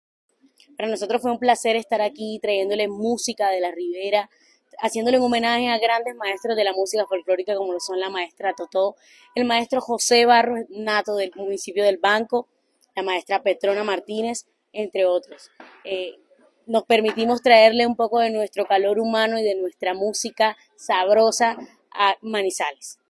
Cantautora